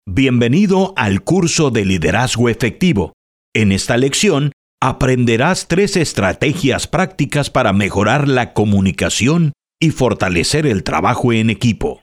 Male
Warm, elegant, smooth, and trustworthy — with a distinctive professional edge.
E-Learning
EspañOl: Voz Clara, Profesional Y DidáCtica. Ideal Para Cursos En LíNea, Capacitaciones Y Contenido Educativo. English: Clear, Professional, And Instructional Tone. Ideal For Online Courses, Training Modules, And Educational Content.